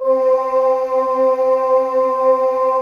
Index of /90_sSampleCDs/USB Soundscan vol.28 - Choir Acoustic & Synth [AKAI] 1CD/Partition C/09-GREGOIRE